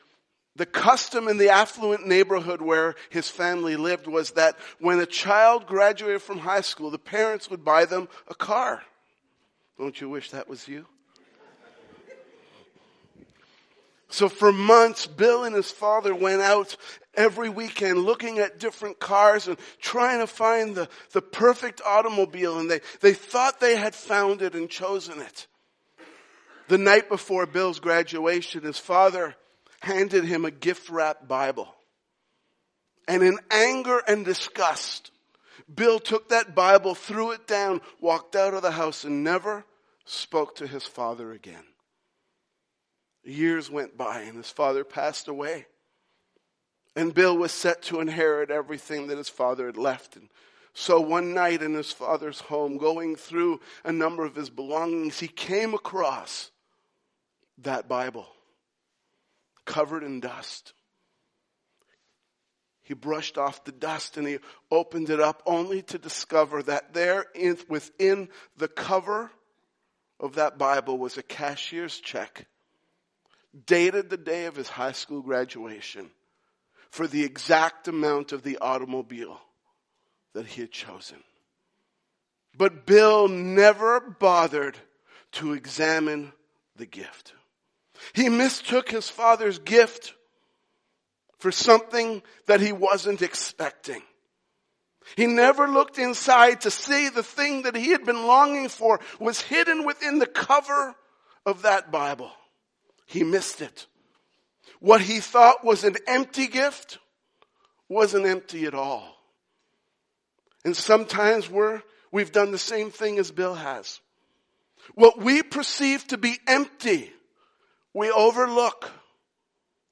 Sermons | Highway Gospel Church